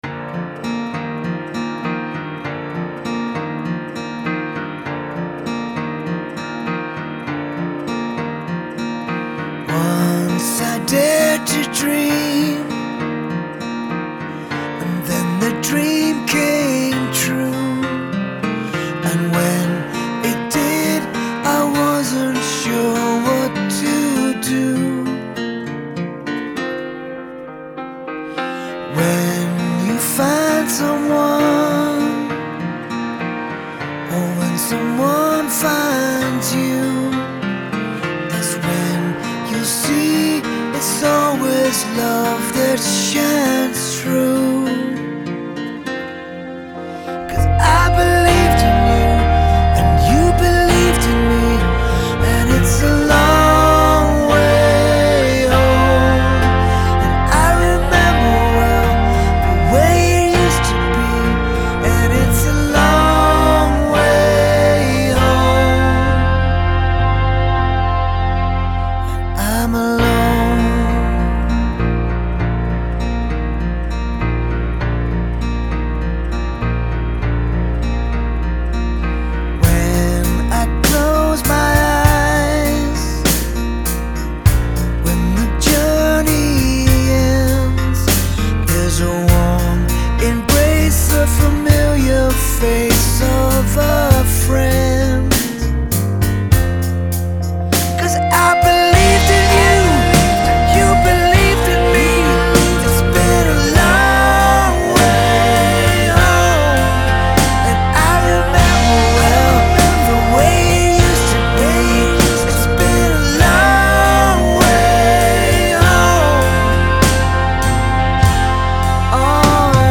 Genre : Comédies musicales